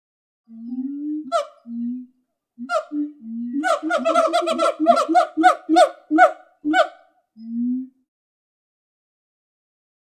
Gibbon Call. Two Gibbons Call With Low Pitched Hoots And High Yelps, More Rapid Than Previous Two. Close Perspective.